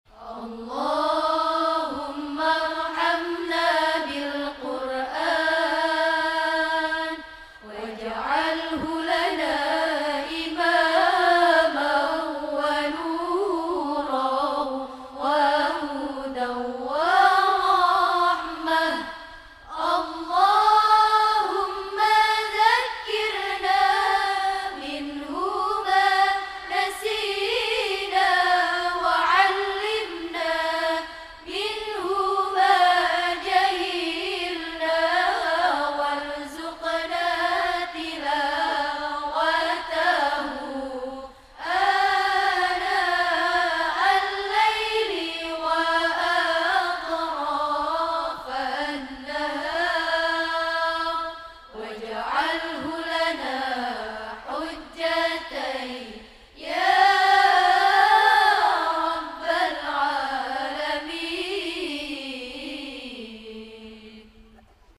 in Masjid Istiqlal Jakarta